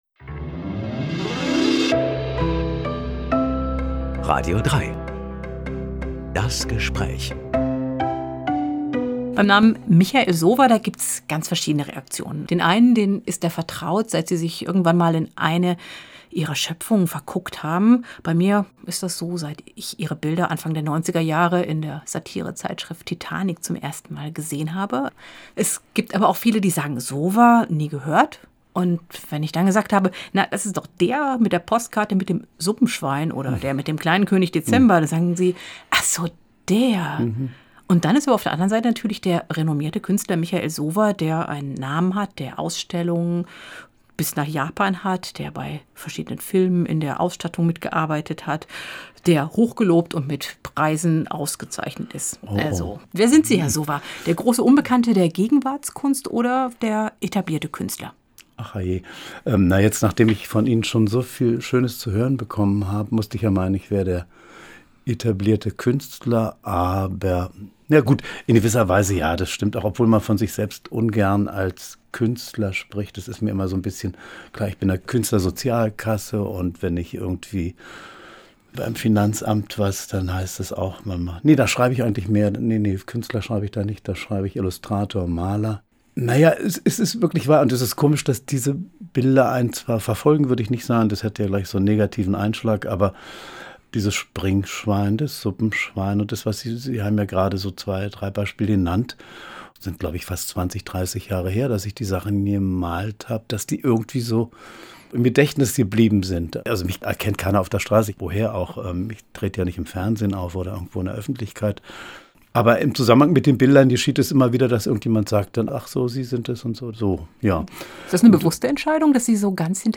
Reden mit einem Menschen. Eine knappe Stunde lang.